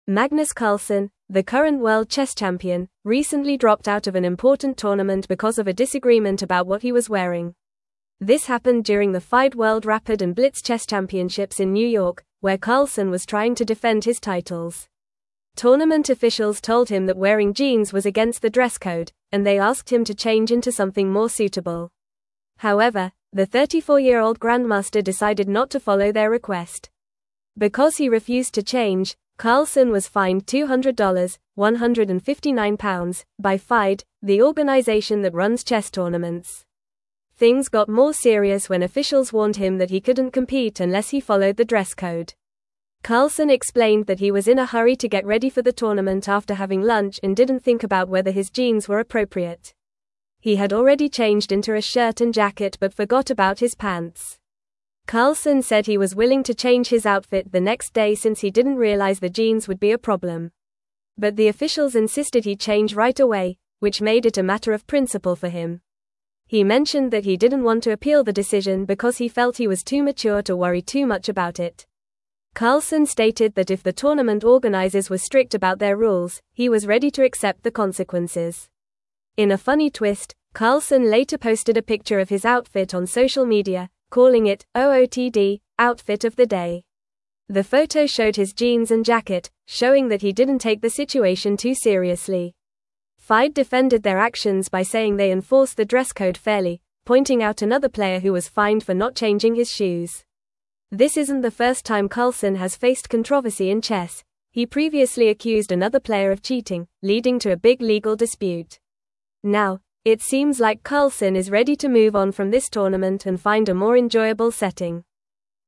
Fast
English-Newsroom-Upper-Intermediate-FAST-Reading-Carlsen-Withdraws-from-Tournament-Over-Dress-Code-Dispute.mp3